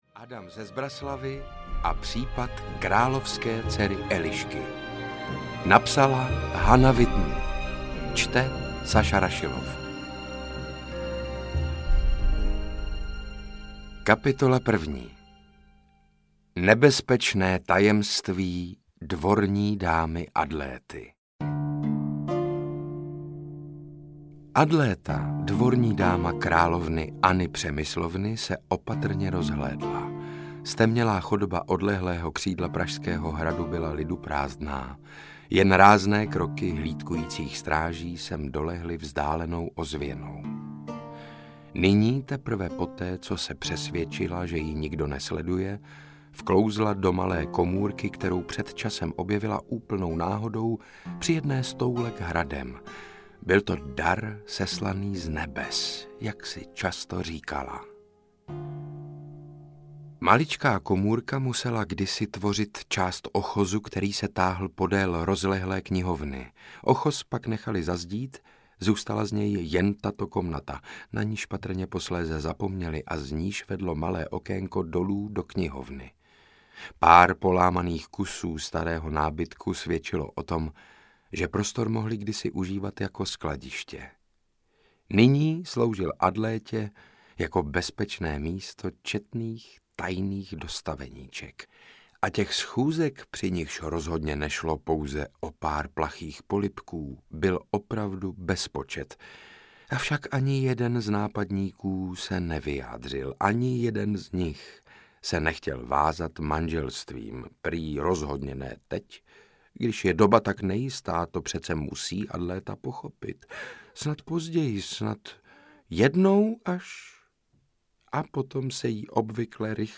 Adam ze Zbraslavi a případ královské dcery Elišky audiokniha
Ukázka z knihy
• InterpretSaša Rašilov